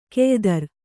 ♪ keydar